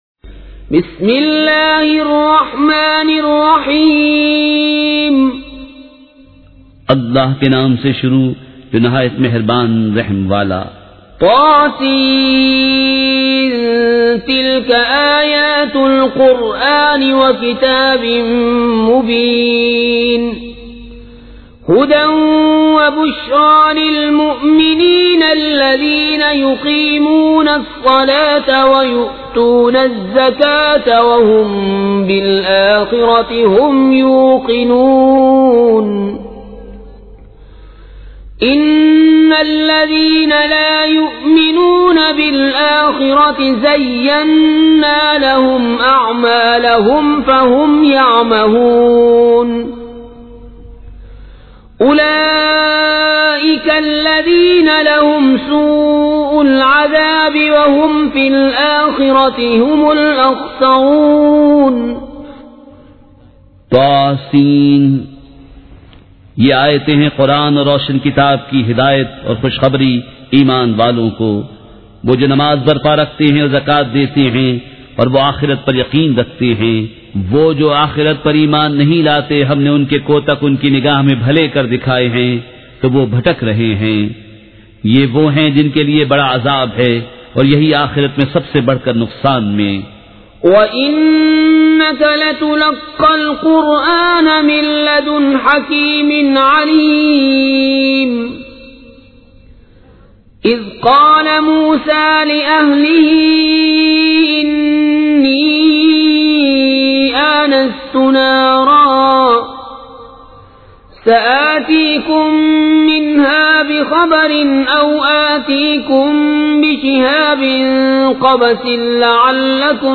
سورۃ النمل مع ترجمہ کنزالایمان ZiaeTaiba Audio میڈیا کی معلومات نام سورۃ النمل مع ترجمہ کنزالایمان موضوع تلاوت آواز دیگر زبان عربی کل نتائج 2608 قسم آڈیو ڈاؤن لوڈ MP 3 ڈاؤن لوڈ MP 4 متعلقہ تجویزوآراء